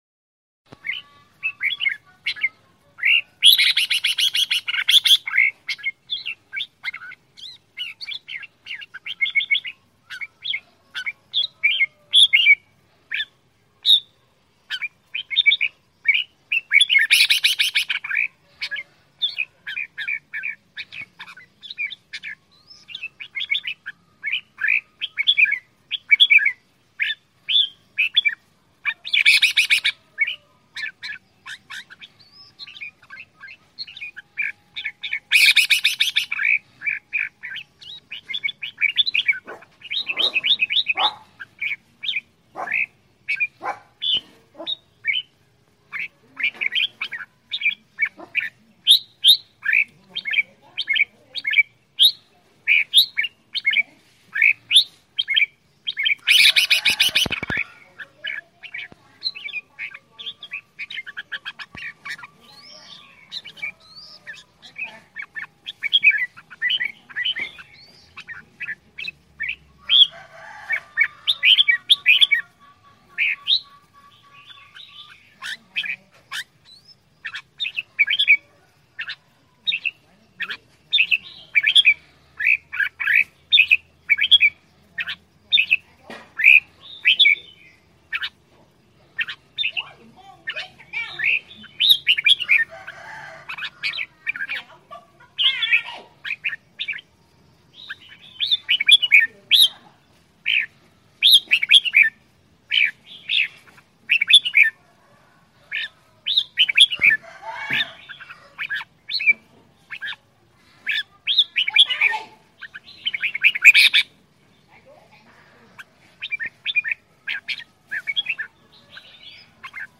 เสียงนกกรงหัวจุกเวียดนาม
เสียงนกกรงหัวจุกตัวเมียร้องเรียกหาคู่ เสียงชัด100% mp3 เสียงนกกรงหัวจุกตัวเมีย .mp3 4sh ฟรี
หมวดหมู่: เสียงนก